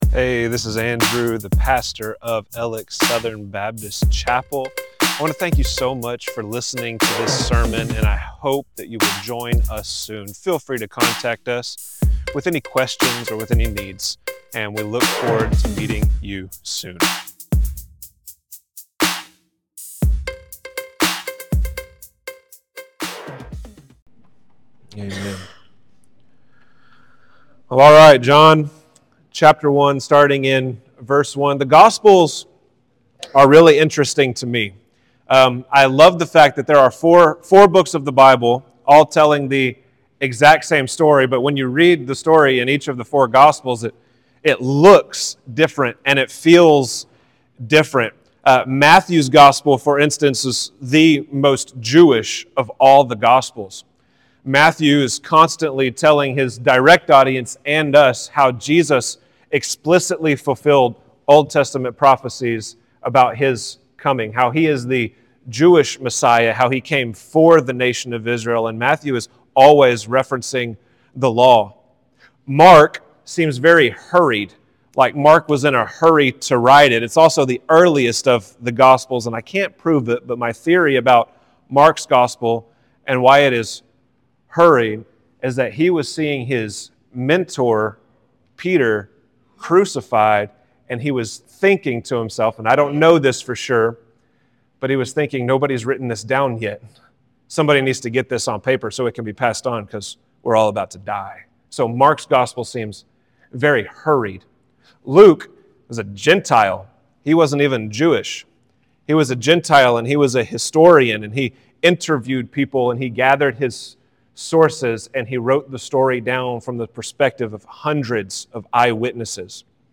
Alex Southern Baptist Chapel Sermons